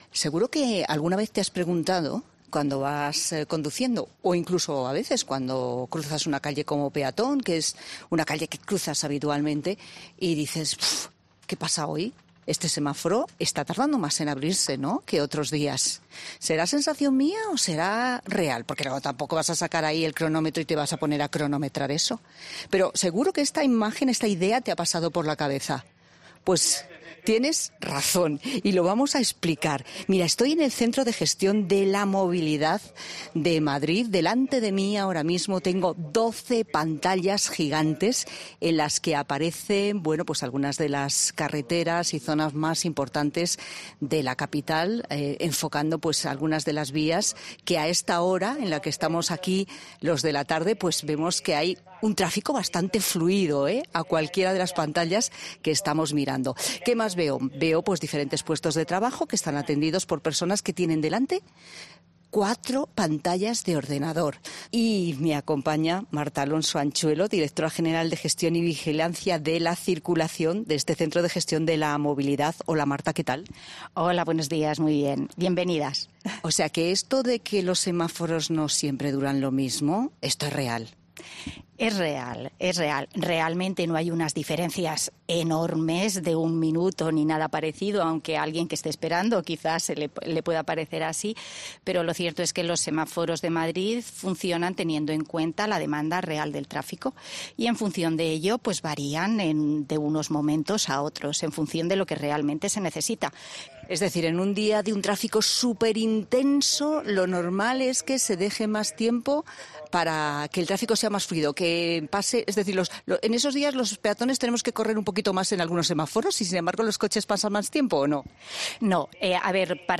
En lugares como Madrid, donde existen grandes tramos de circulación y muchos vehículos, 'La Tarde' se ha desplazado para conocer a las personas que...